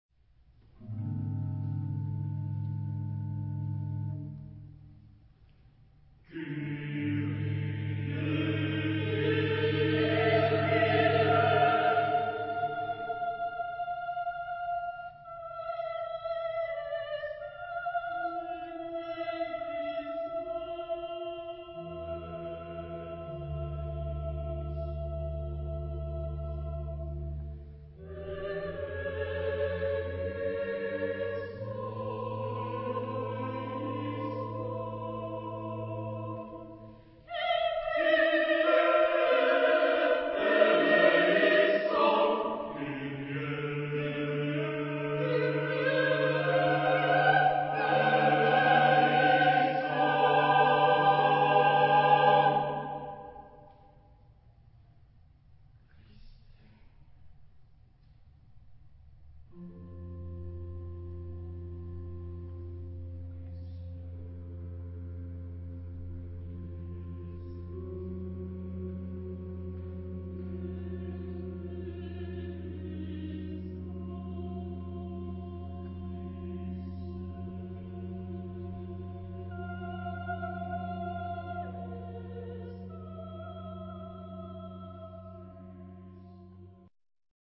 Epoque: 20th century
Genre-Style-Form: Mass ; Sacred
Mood of the piece: expressive
Type of Choir: SSSAAATTTBBBB  (13 mixed voices )
Instrumentation: Organ (optional)